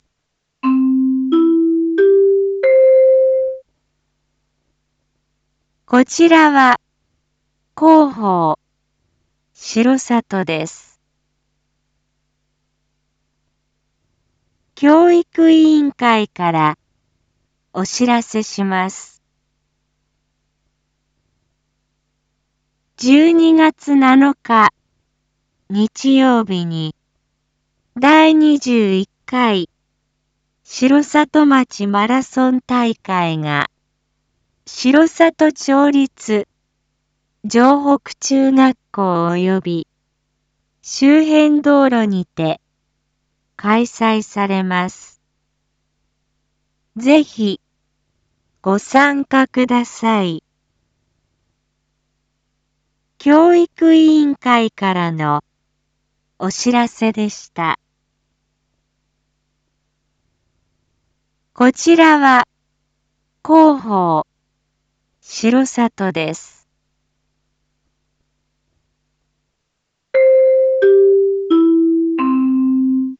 一般放送情報
Back Home 一般放送情報 音声放送 再生 一般放送情報 登録日時：2025-11-04 07:01:14 タイトル：第21回城里町マラソン大会開催③ インフォメーション：こちらは広報しろさとです。